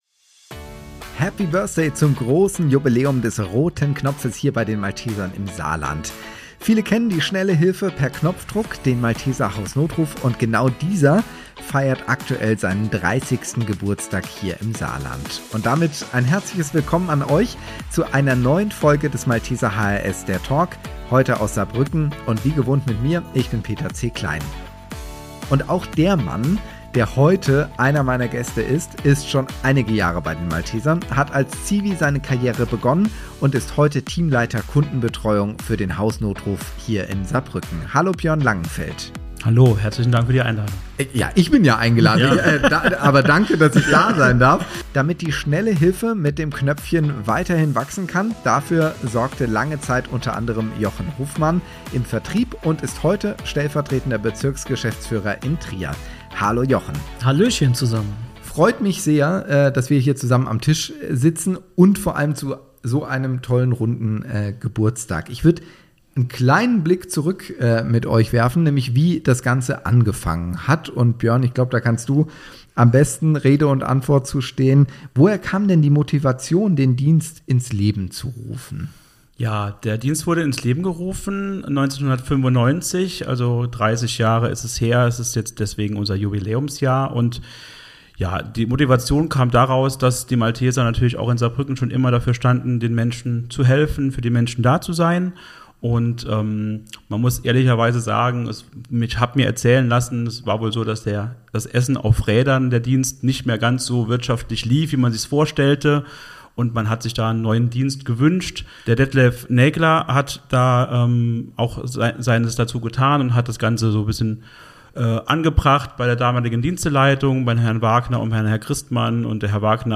Im Talk erzählen die beiden Gäste wie sich der Hausnotruf entwickelt hat und welche Aktionen zum Jubiläum geplant sind.